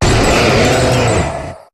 Cri de Séracrawl dans Pokémon HOME.